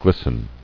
[glis·ten]